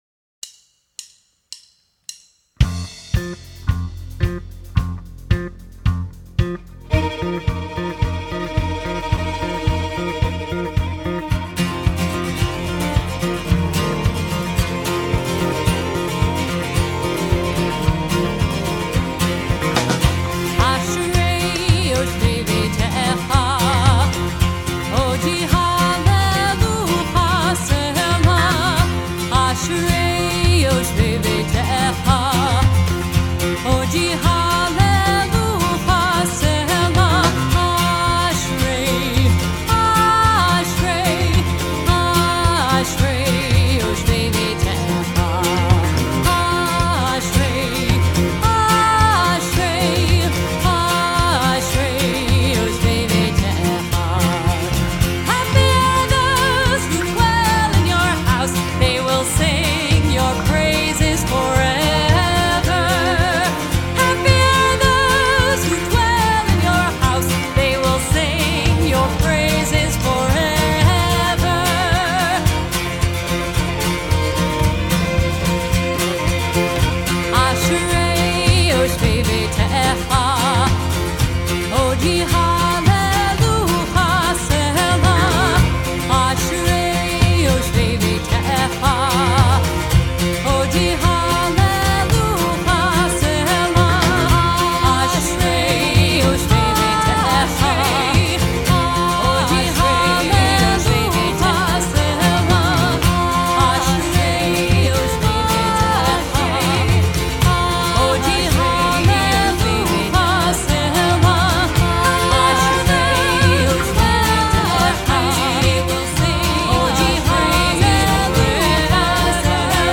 Internationally known contemporary Jewish music